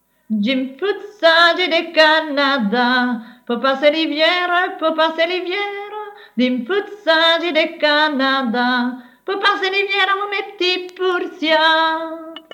Genre : chant
Type : chanson narrative ou de divertissement
Lieu d'enregistrement : Morville
Support : bande magnétique